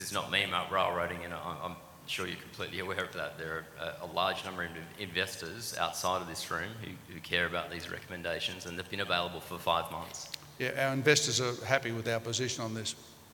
Adelaide, Thursday, 4 May 2017